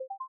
Custom synthesized sounds — no generic notification bleeps here:
▶ Play Ascending arpeggio — victory fanfare (sort of)